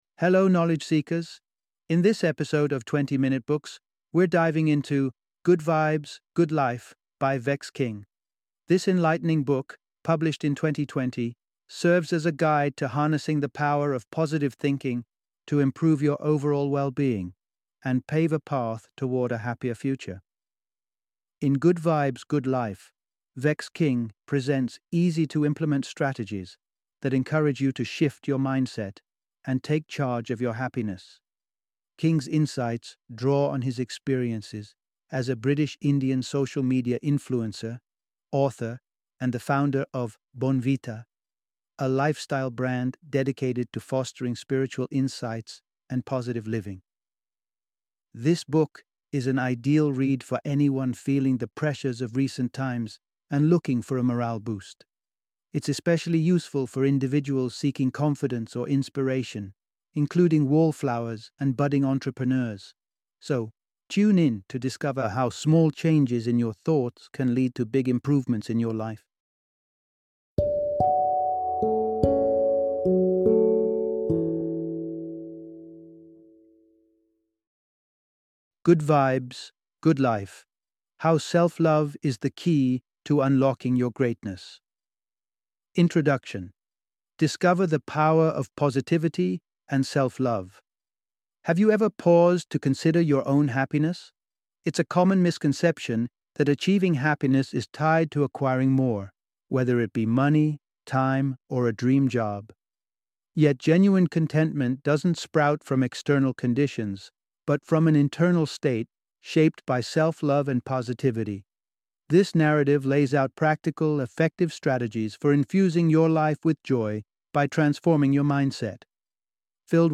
Good Vibes, Good Life - Audiobook Summary